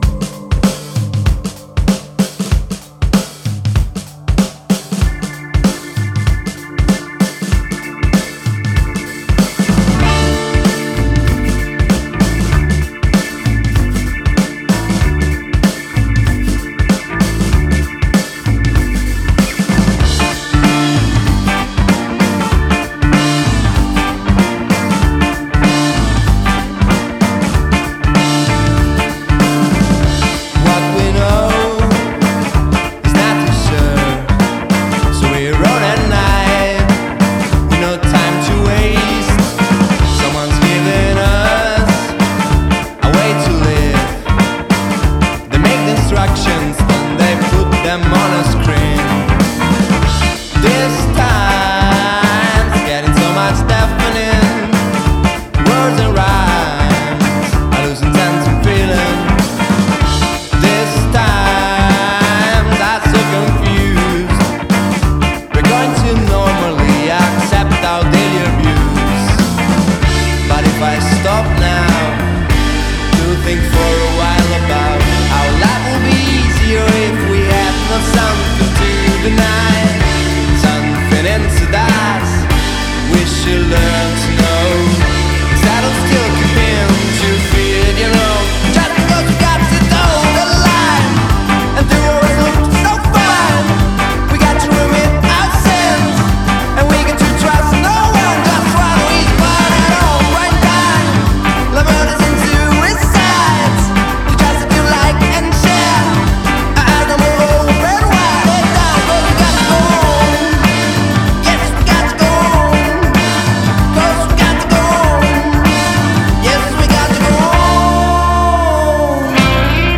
et fiers représentants des trios guitare/basse/batterie
des compositions entraînantes et bon nombre de riffs funkys